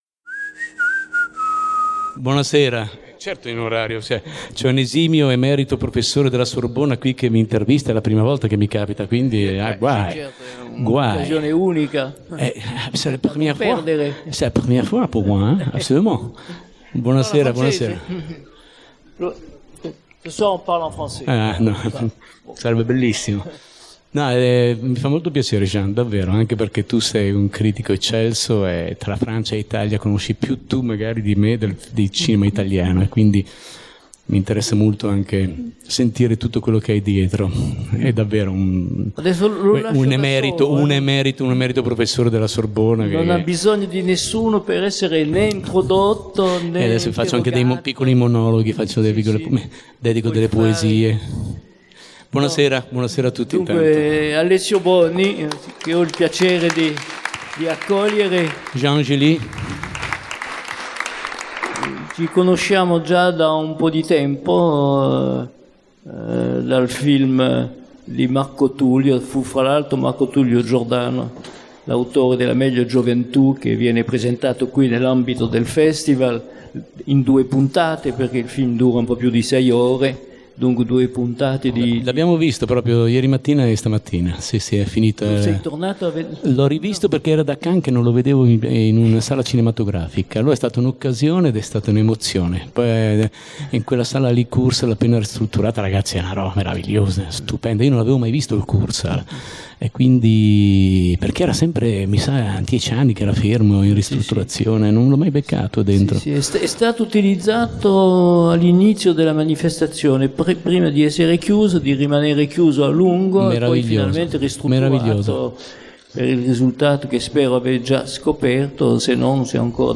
Il ruolo dell'attore e dei personaggi interpretati. L'attore Alessio Boni dialoga con il critico cinematografico Jean Gili.